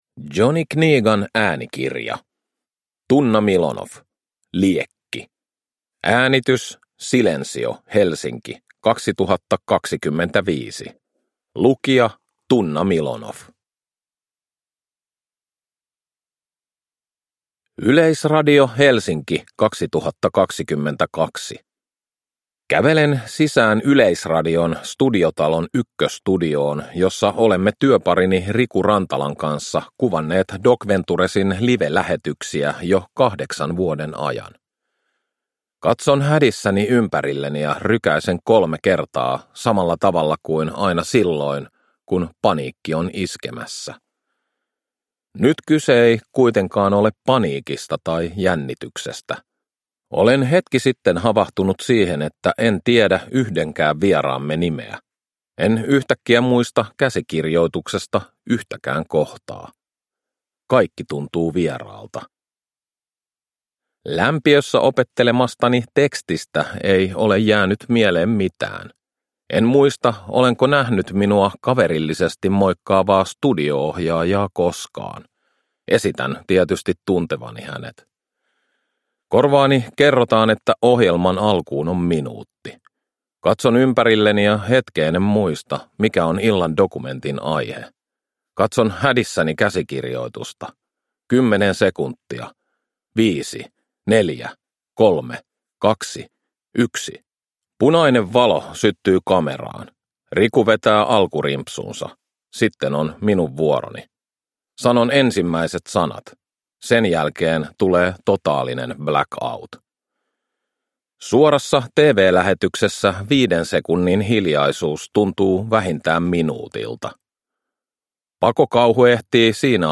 Liekki – Ljudbok
Uppläsare: Tuomas Milonoff